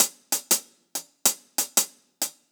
Index of /musicradar/ultimate-hihat-samples/95bpm
UHH_AcoustiHatB_95-02.wav